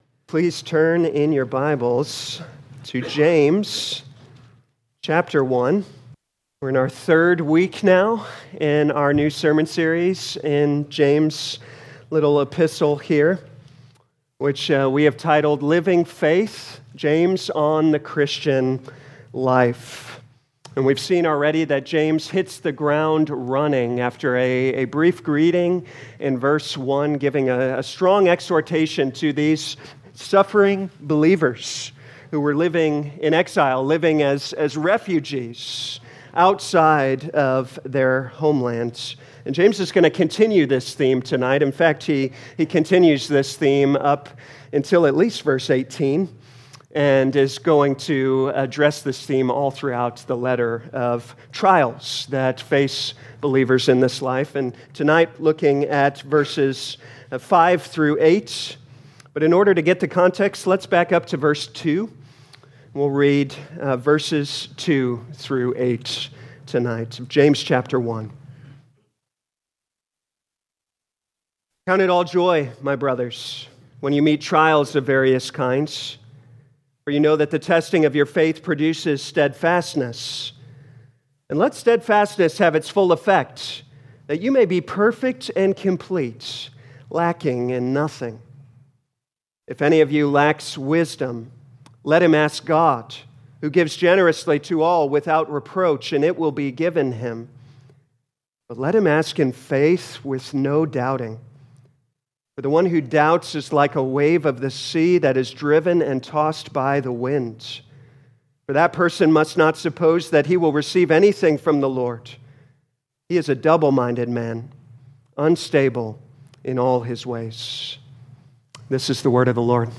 2025 James Evening Service Download